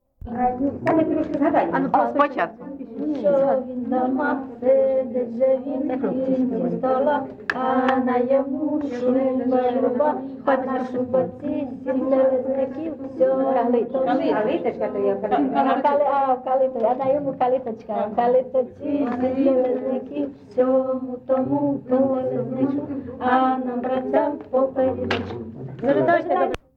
ЖанрЩедрівки
Місце записус. Писарівка, Золочівський район, Харківська обл., Україна, Слобожанщина